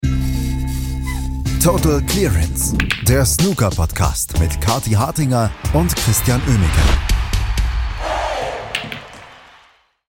aus dem Tempodrom zusammen, haben Shaun Murphy im Interview und